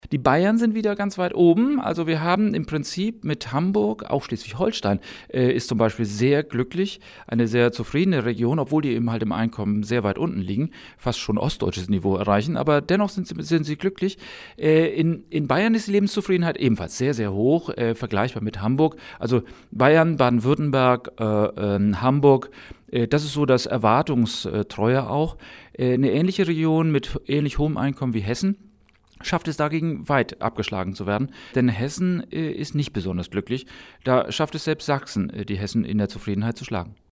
O-Ton: Glücksatlas